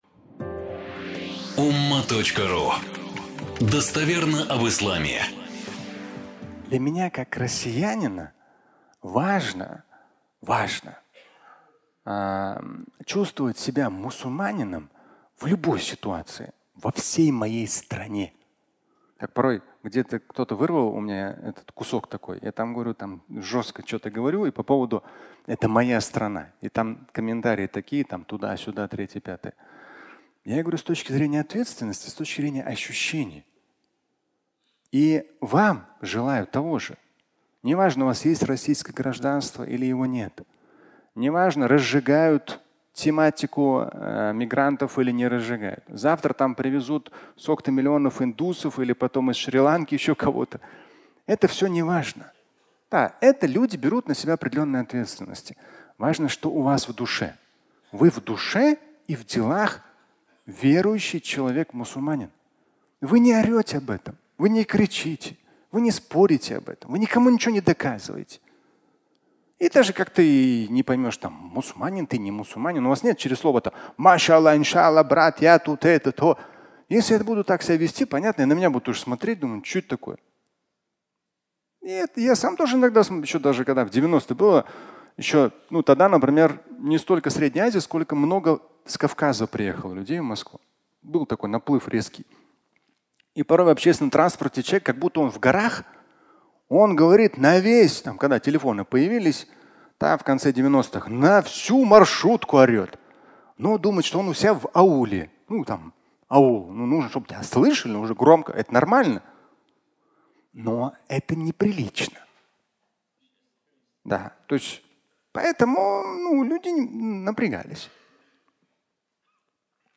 Мигранты (аудиолекция)